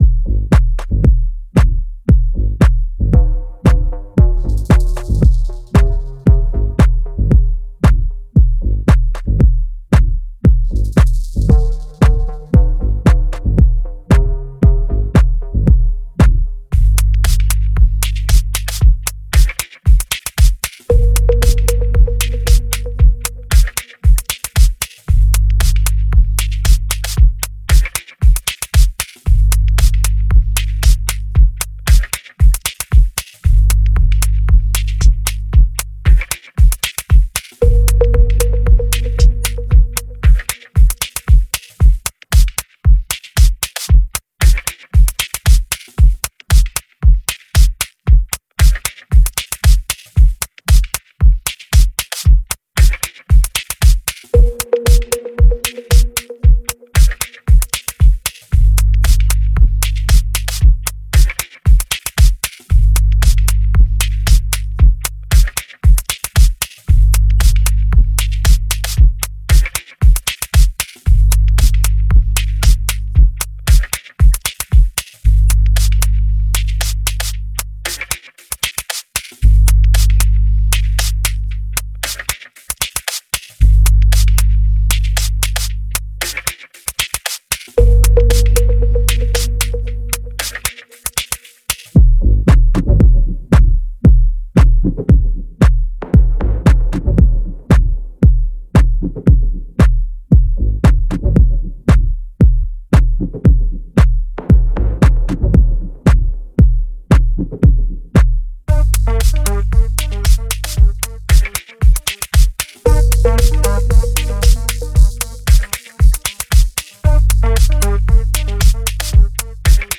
Deep House, Bass Boosted
Танцевальная Музыка